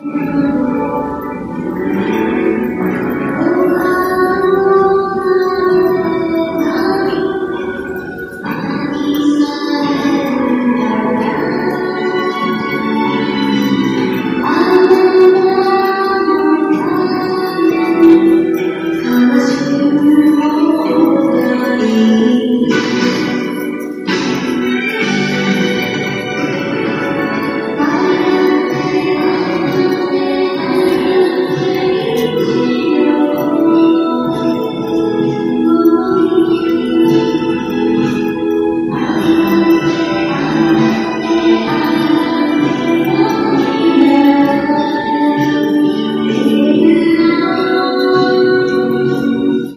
早朝の誰もいない柏崎駅の地下連絡道
ってことで通ってたんですけどこの地下道、めちゃくちゃ響くｗｗ
筒石の1.5倍は響くレベルｗｗ
そしてどこからともなく何かが地下道で鳴り響く謎の曲ｗｗ